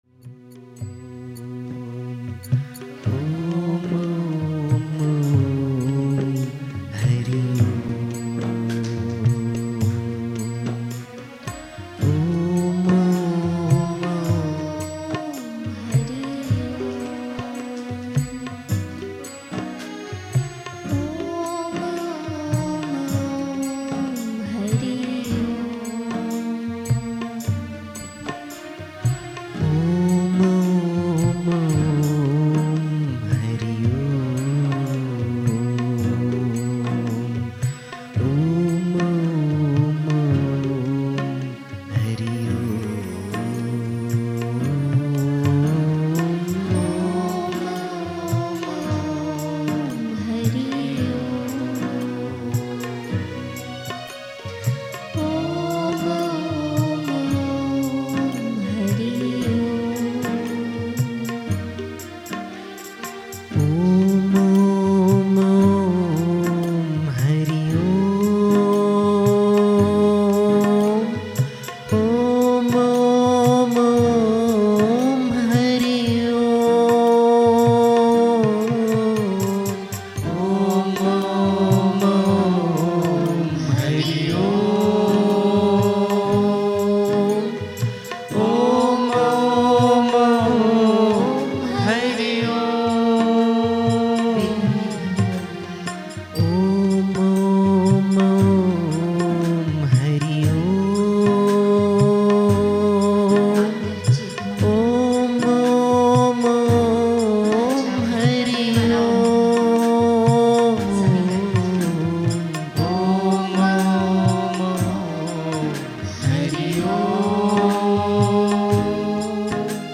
Kirtan - Sant Shri Asharamji Bapu Kirtan podcast
Omkar-Kirtan-Peace-And-Meditation.mp3